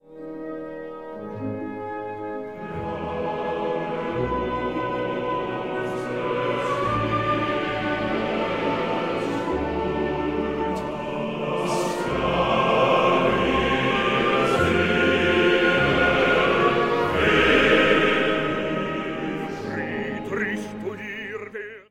Richard Wagner – Sueño de Elsa (1850) Deja un comentario Tonalidad inicial: La♭ mayor. Duración: 8 compases 4/4. Cambios: 14.